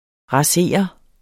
Udtale [ ʁɑˈseˀʌ ]